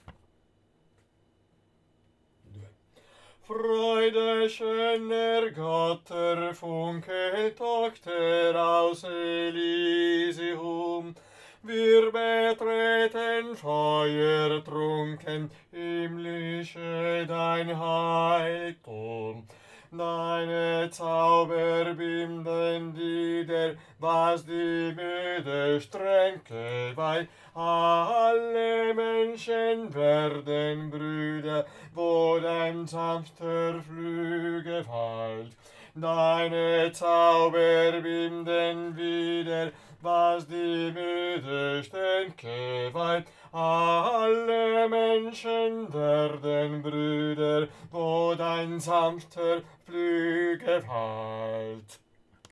Versailles - Bassi
inno_alla_gioia_BASS.mp3